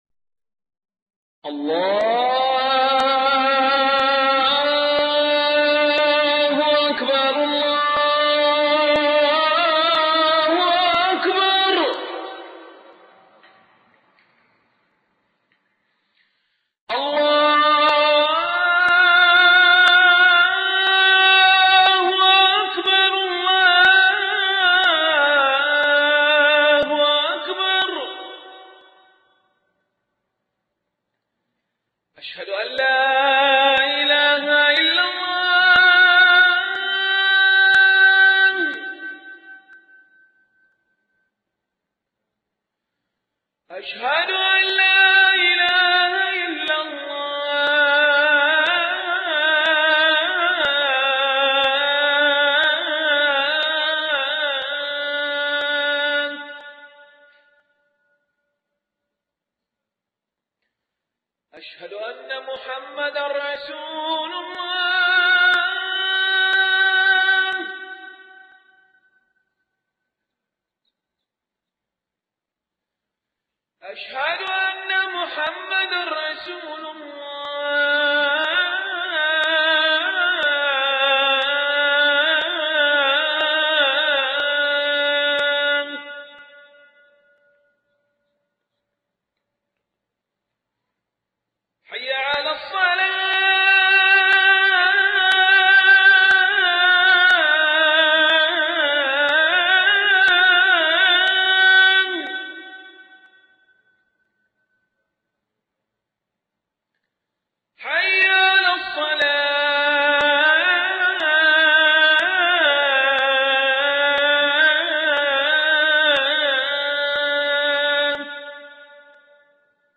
أذان القارئ الشيخ علي بن أحمد ملا